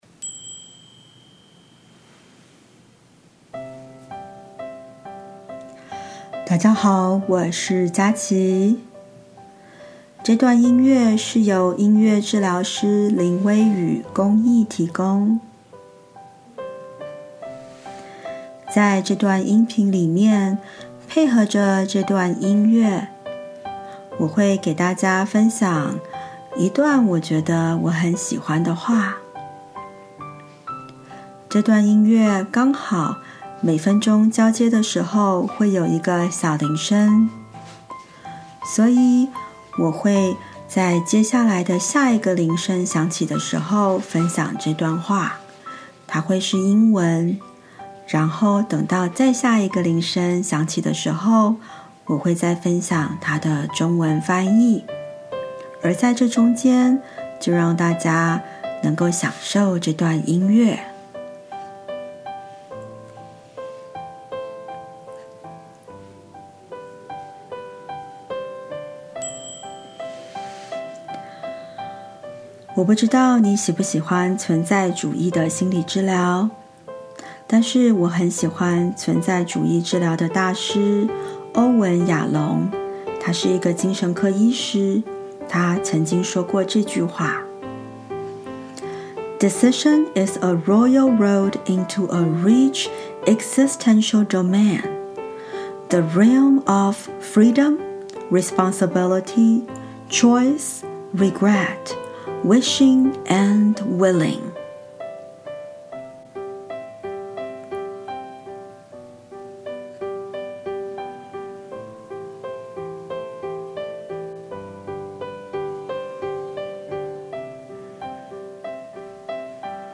五分鐘簡短的文字與音樂